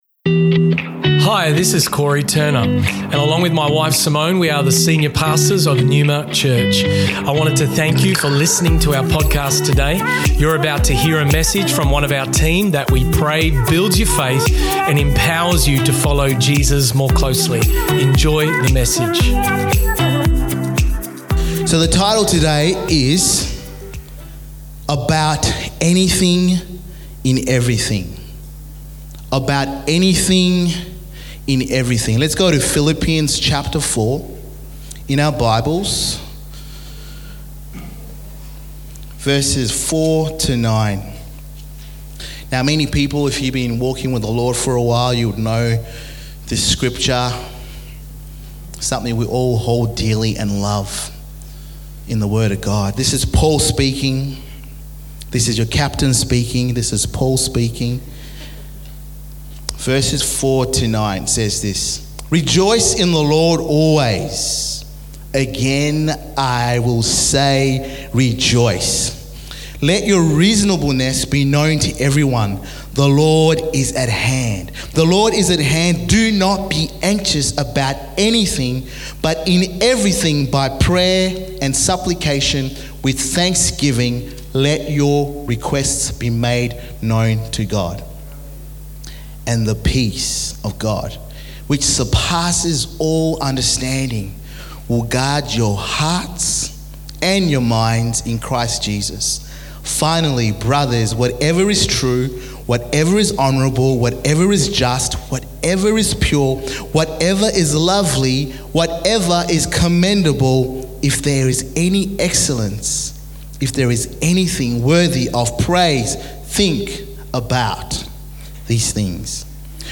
Neuma Church Melbourne South Originally Recorded at the 10am Service on Sunday 3rd September 2023.&nbsp